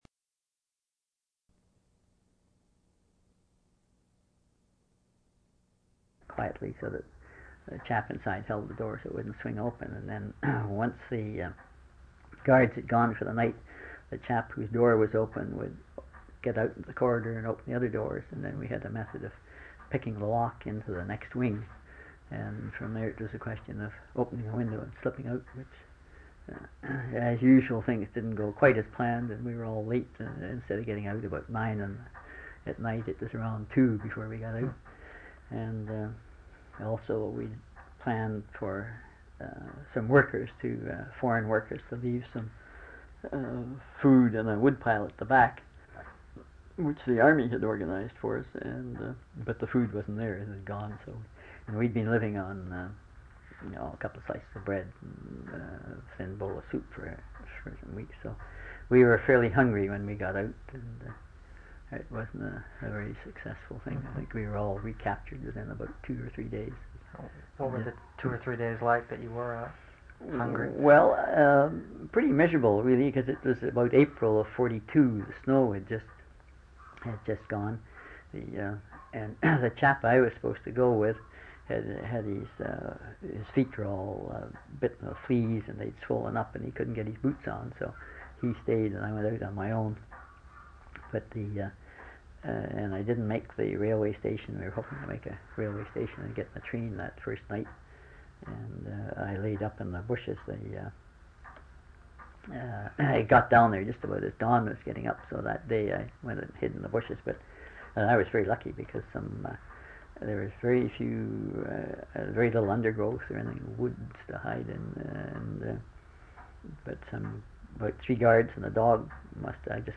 In Collection: Canadian Military Oral Hist